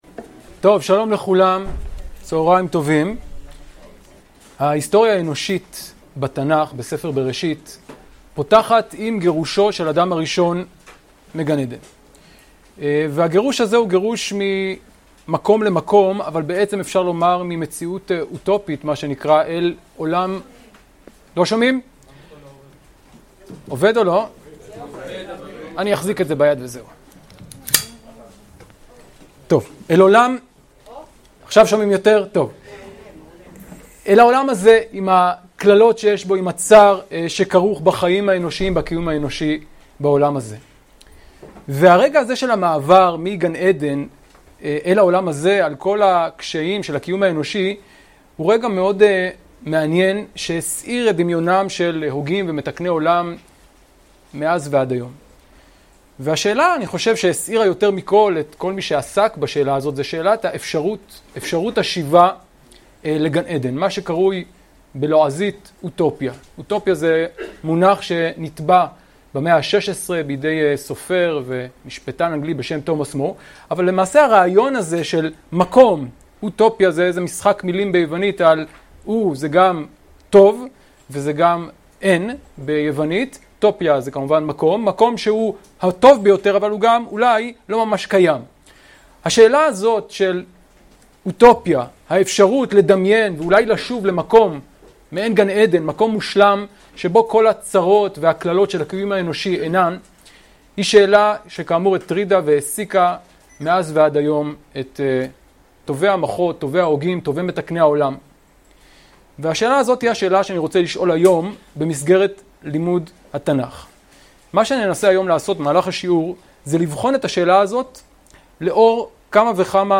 השיעור באדיבות אתר התנ"ך וניתן במסגרת ימי העיון בתנ"ך של המכללה האקדמית הרצוג תשפ"ב